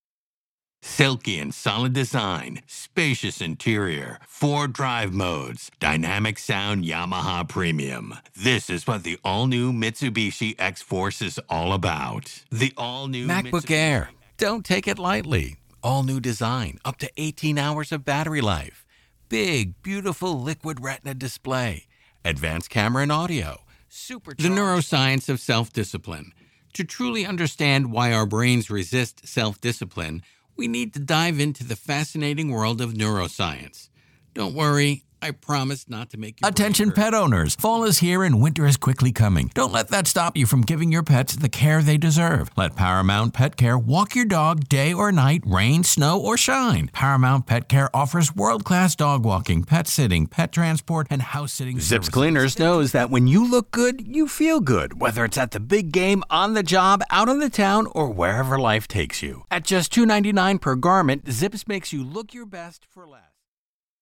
I have a real, relatable, trustworthy mid-range voice. My intelligent, inquisitive reads are perfect for roles like teachers, scientists, and narrators.